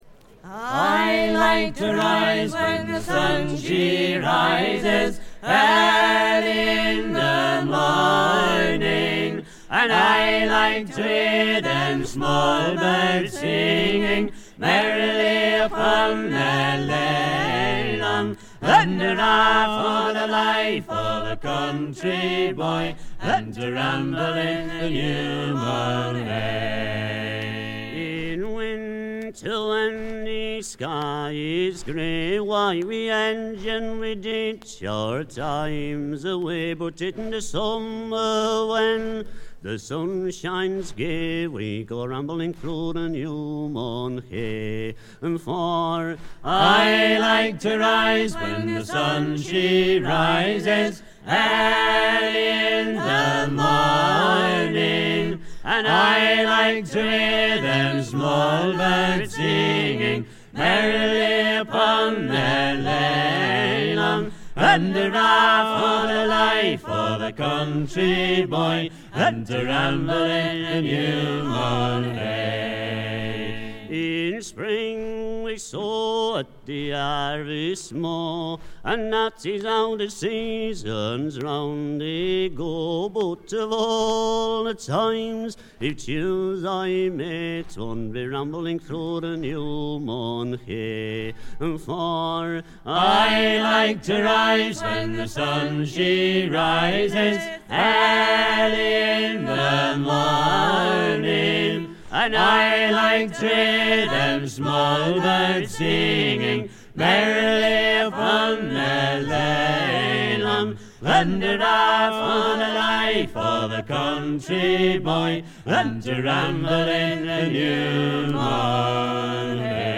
部分試聴ですが、軽微なチリプチが少し出る程度。
スイスのフォーク・フェスティヴァルの2枚組ライヴ盤。
試聴曲は現品からの取り込み音源です。